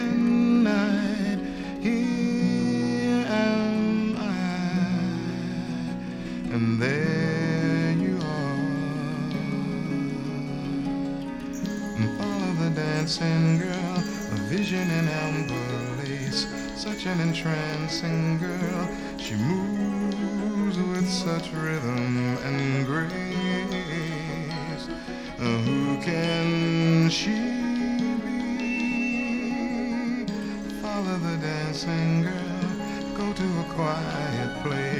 Жанр: Рок / R&B / Джаз / Соул